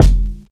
Kicks
brk_kick_stack.wav